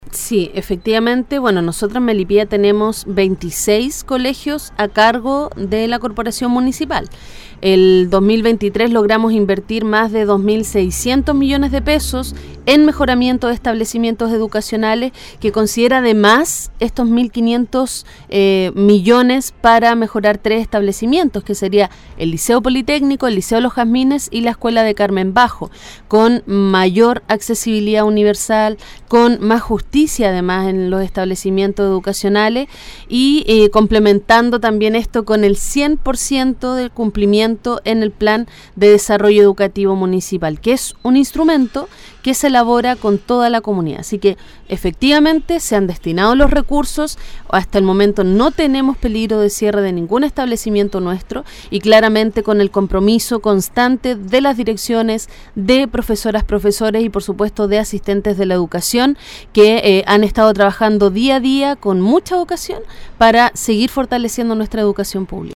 Durante una entrevista realizada en “La Mañana de Todos” de Radio Ignacio Serrano, la alcaldesa Olavarría respondió a las dudas  más importantes para la comuna de Melipilla